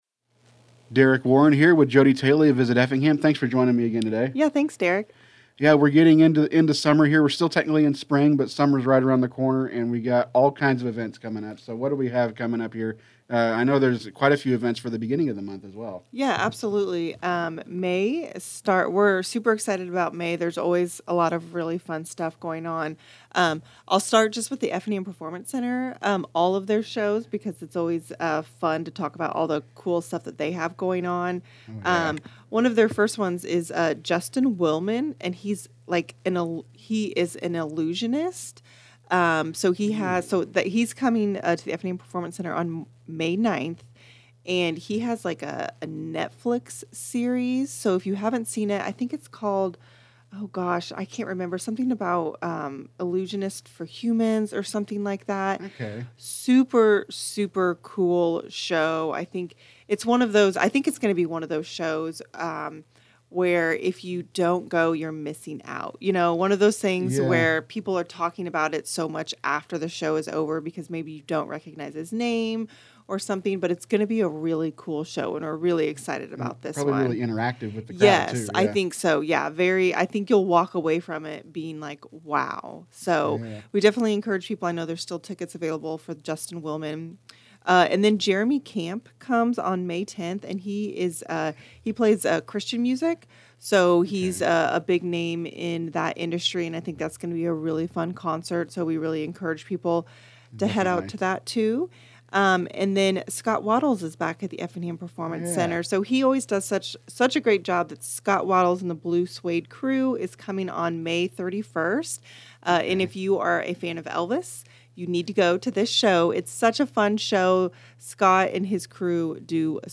This is part of a monthly series of interviews that will continue next month.